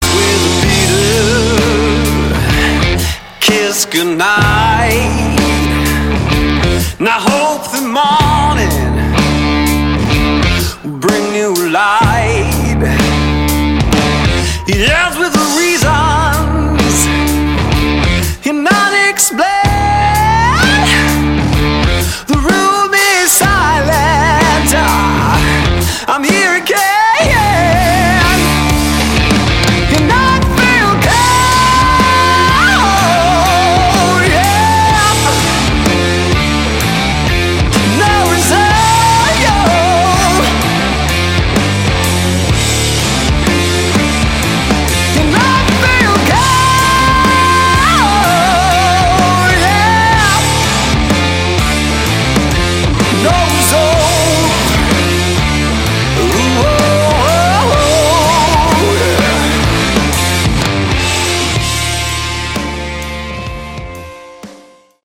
Category: Bluesy Hard Rock
vocals, harmonica
guitar, backing vocals
drums
bass